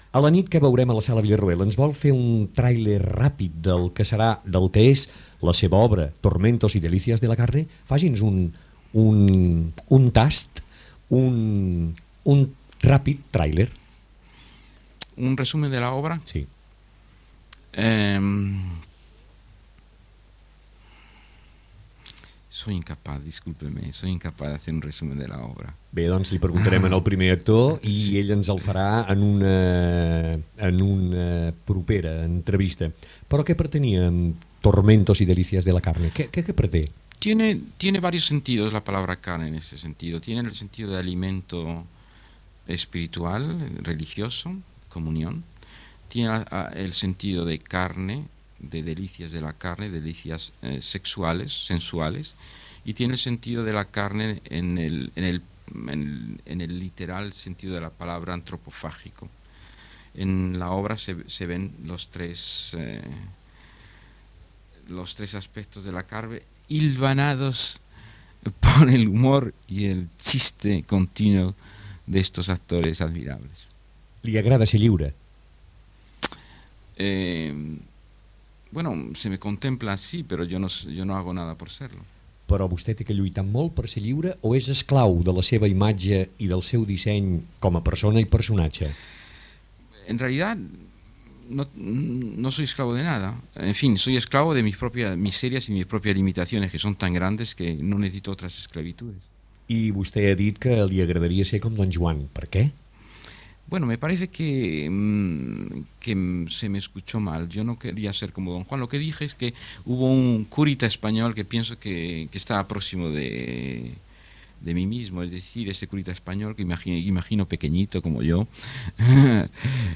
Entrevista al dramaturg Fernando Arrabal sobre l'obra de teatre "Tormentos y delicias de la carne" i la seva personalitat literària
Entreteniment
FM